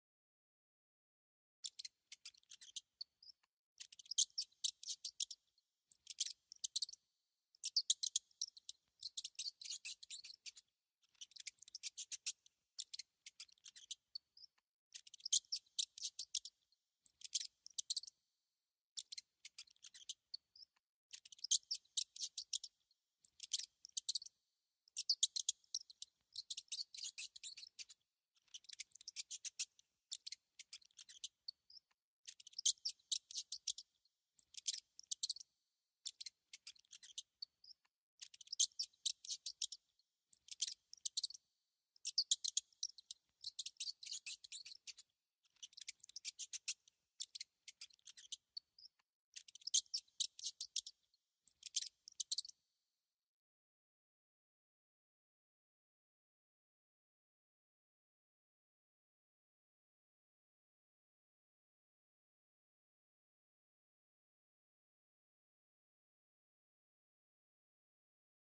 جلوه های صوتی
دانلود صدای موش 1 از ساعد نیوز با لینک مستقیم و کیفیت بالا